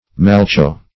\mal"le*cho\ (m[a^]l"l[-e]*ch[=o])